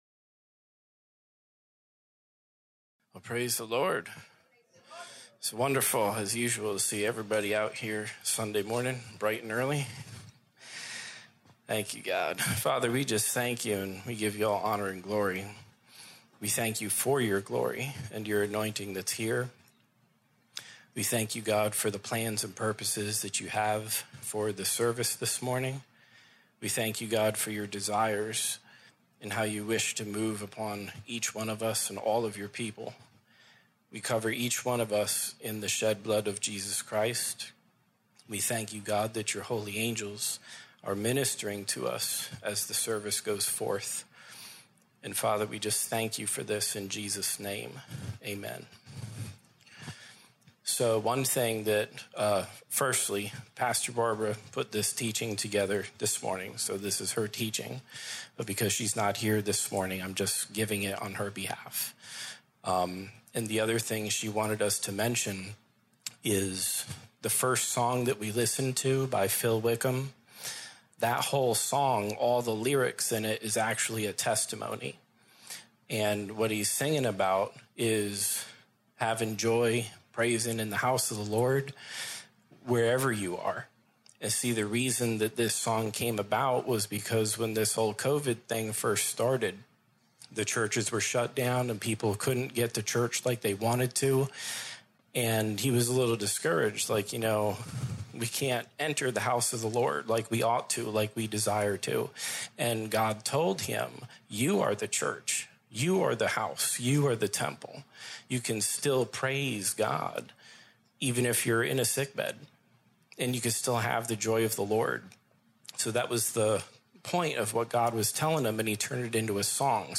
Posted in Sermons